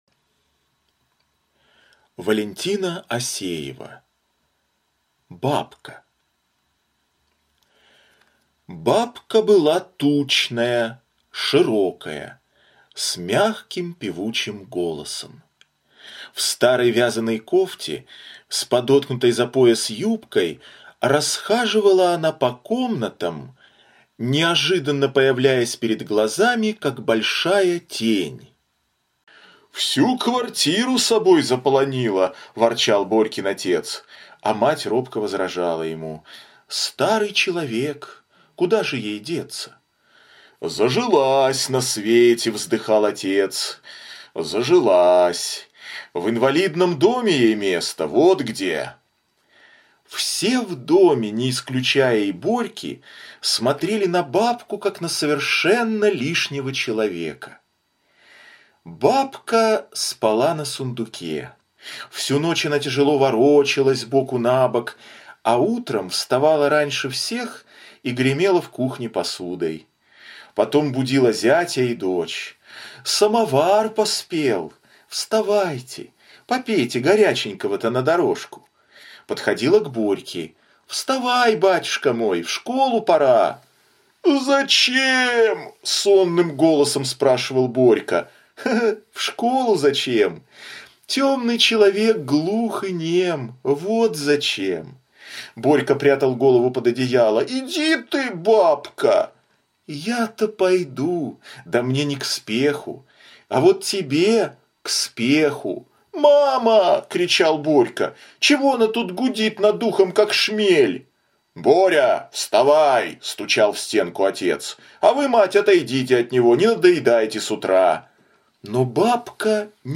Бабка - аудио рассказ Валентины Осеевой - слушать онлайн